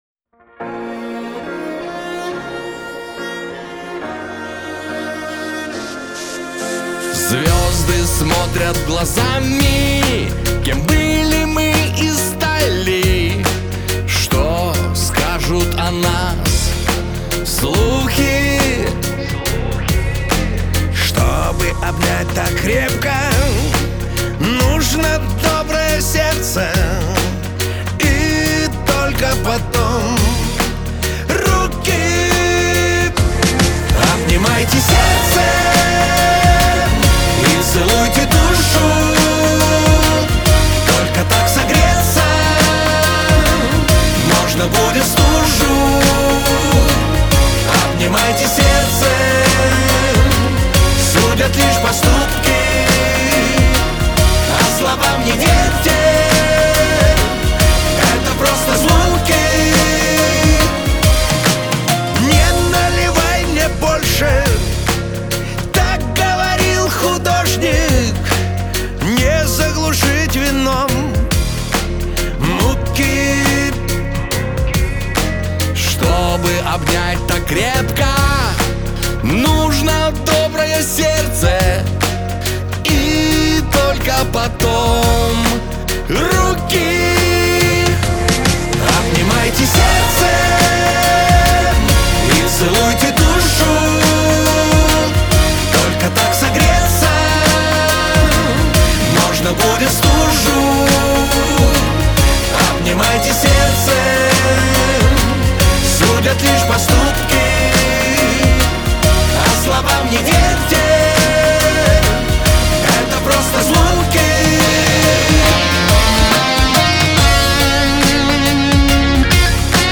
диско , эстрада
pop , дуэт